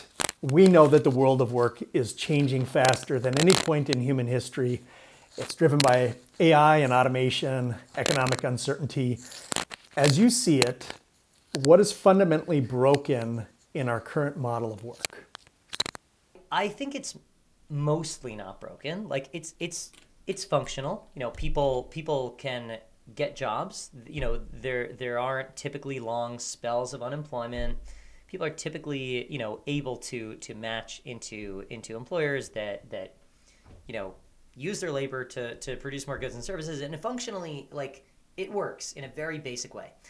This sample demonstrates a complete edit of a real podcast segment, combining all the techniques and more.
Before (Raw Audio):
Uneven levels, room noise, verbal stumbles, and a flat sound.
Raw-Audio-Podcast-Sample.wav